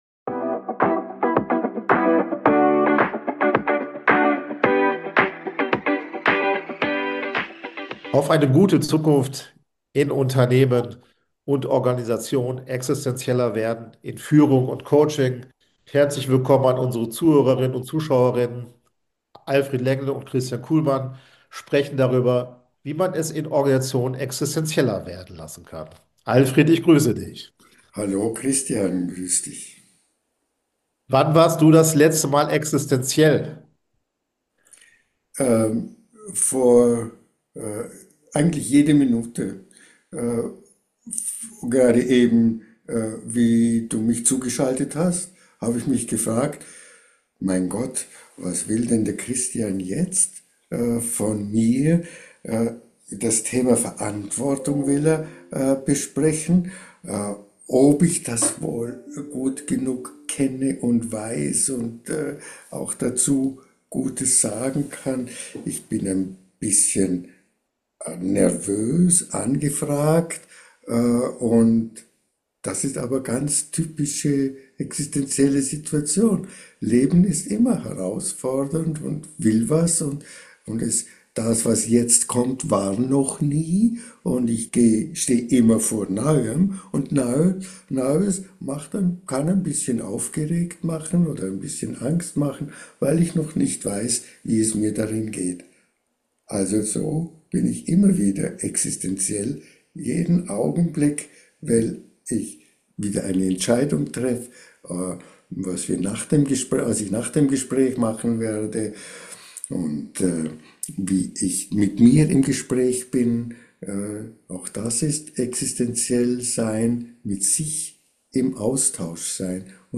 Im Gespräch geht es auch um eine zentrale Frage aus der Führungspraxis: Warum scheuen sich Menschen oft vor Verantwortung?